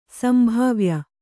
♪ sambhāvya